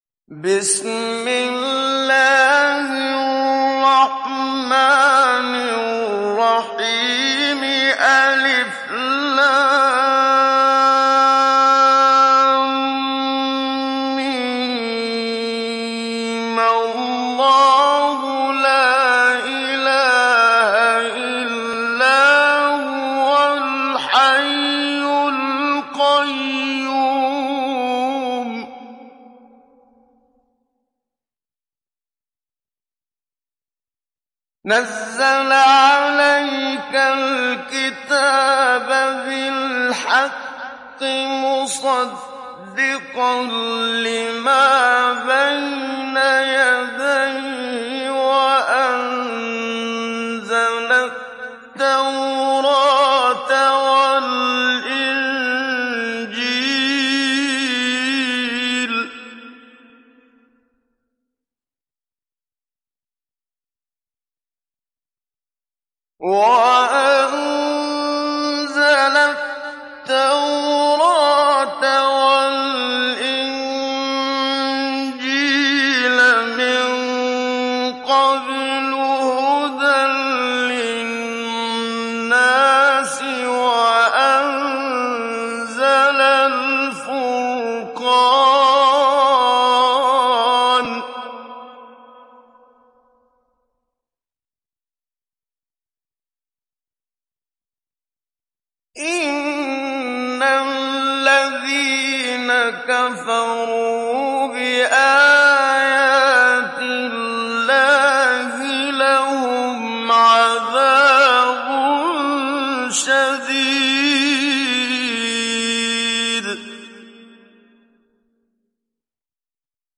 دانلود سوره آل عمران محمد صديق المنشاوي مجود